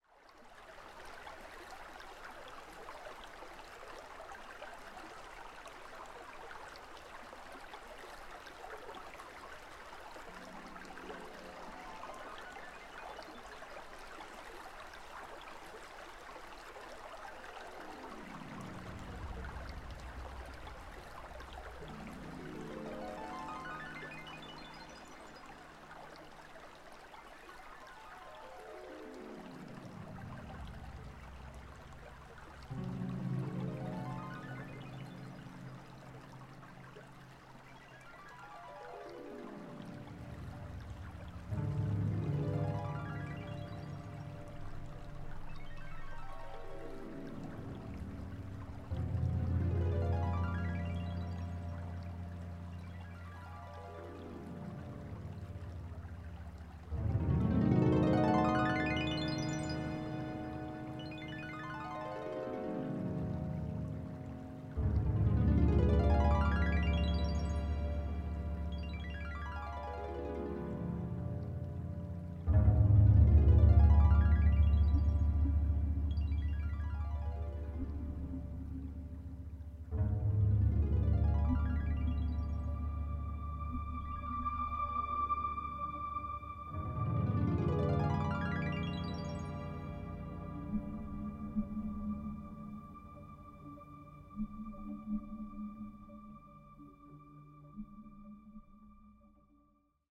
heel ontspannend, met nogal wat natuurgeluiden